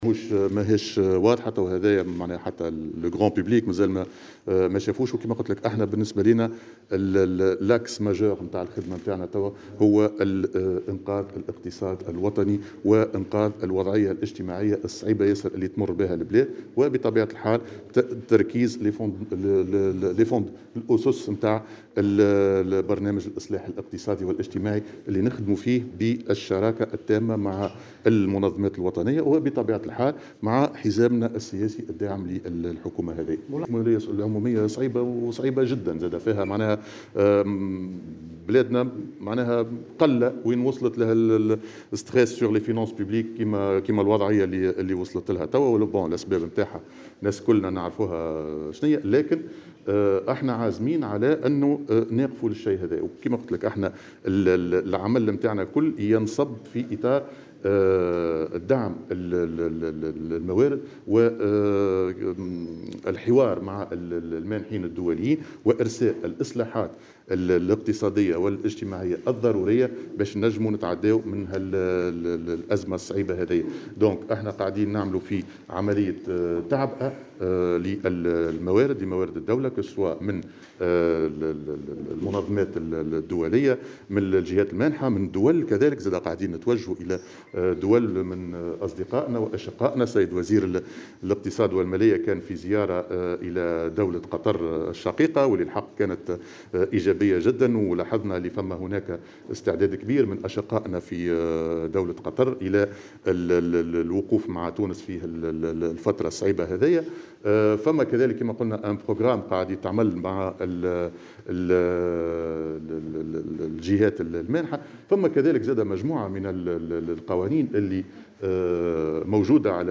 وأضاف في تصريح اليوم لمراسل "الجوهرة أف أم" اثر اجتماعه بالفاعلين الاقتصاديين في ييت الحكمة، أن هدف الحكومة الحالي هو تركيز أسس برنامج الإصلاح الاجتماعي والاقتصادي الذي يتم العمل عليه بالشراكة مع المنظمات الوطنية والحزام السياسي للحكومة من أجل تقديمه للجهات المانحة.